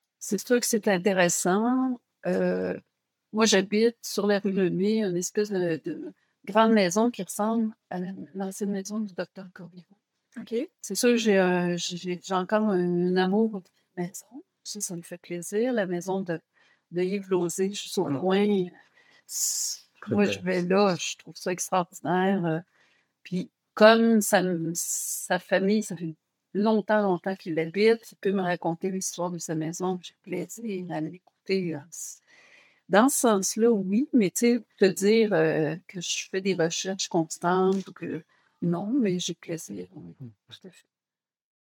Audio excerpt: Interview